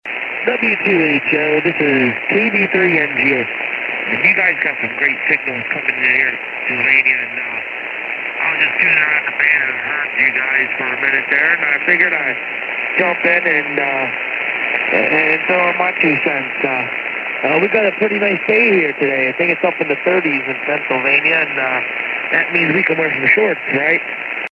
These are some audio samples of the Orange County Amateur Radio Club Net which meets Sundays at 12 noon on 3.920 MHz LSB.
Antenna: 75 meter dipole/Johnson Matchbox
Radio: ICOM IC745 transceiver/SB 200 amplifier
Signals are typically S5 to 10 over S9